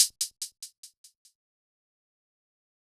Closed Hats
Hihat (Different).wav